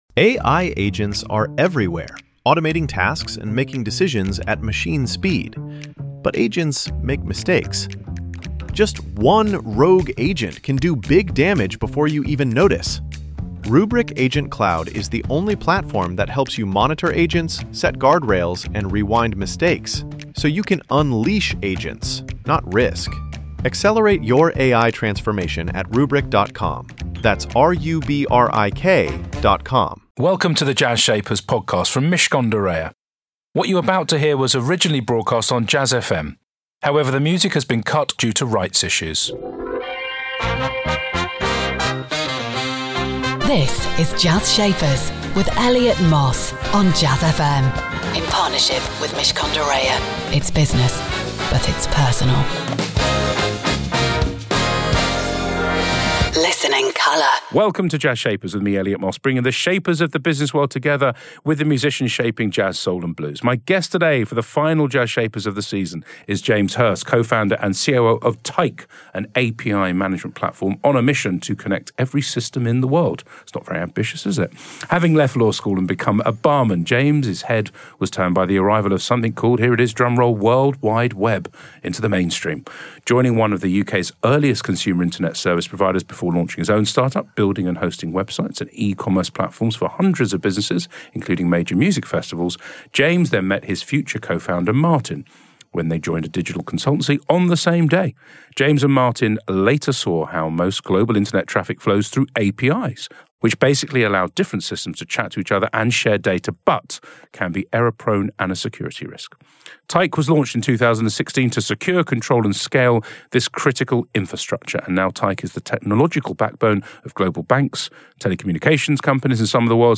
Jazz Shapers features interviews with founders - business shapers – who, like the shapers of jazz, soul and blues have defied convention, broken the mould and gone on to achieve great success.